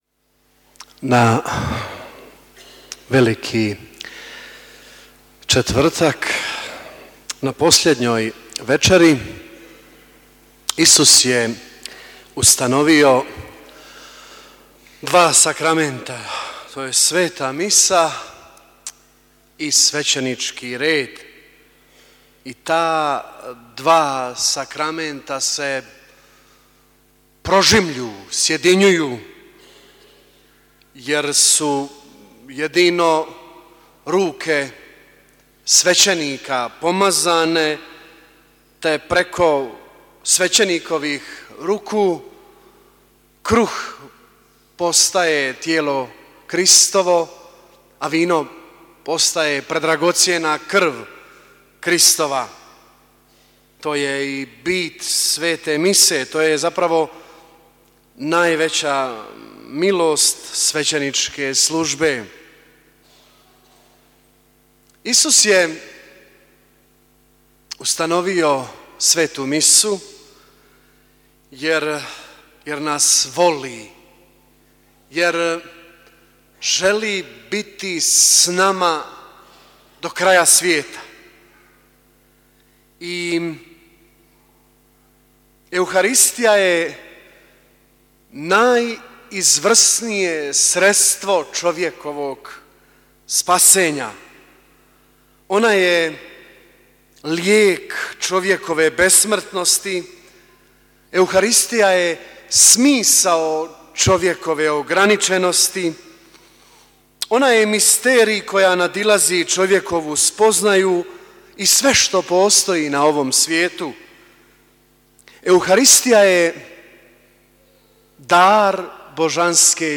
Propovijed-Veliki-cetvrtak.mp3